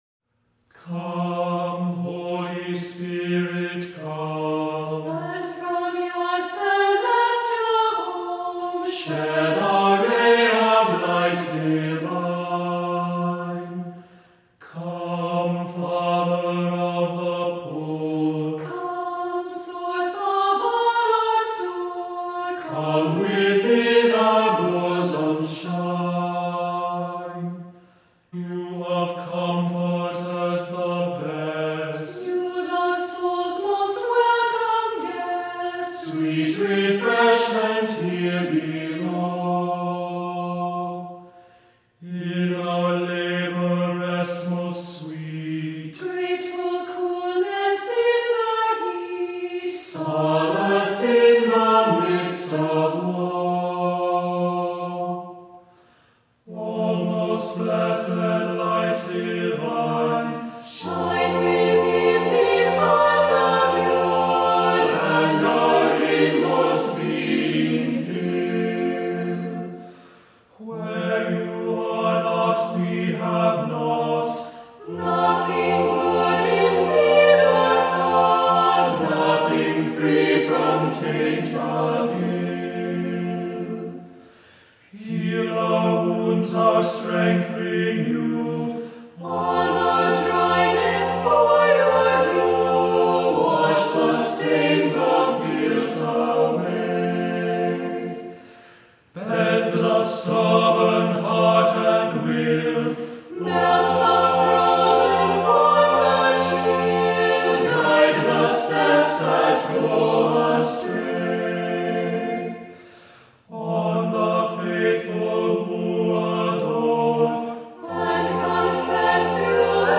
Voicing: SATB a cappella, assembly